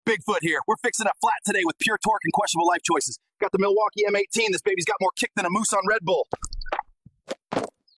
AI Gorilla Fixing A Tire sound effects free download